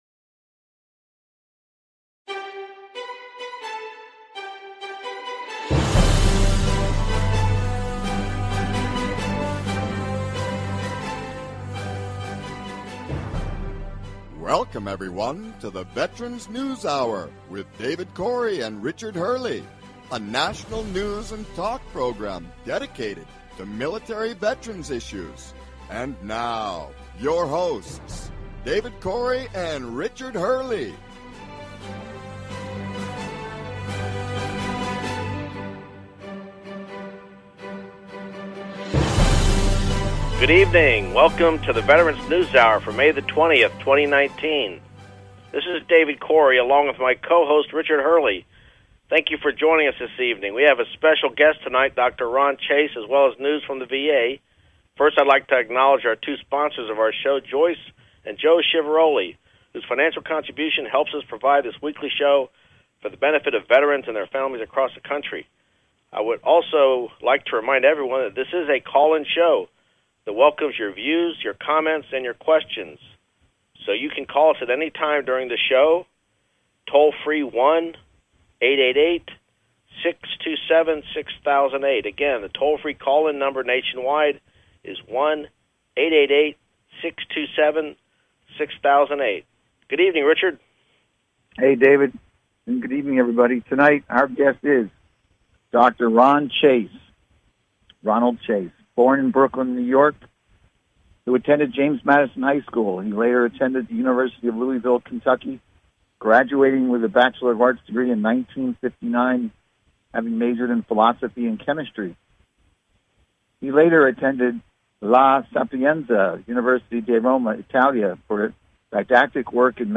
Talk Show Episode
News and talk show about military veterans issues, including VA benefits and all related topics.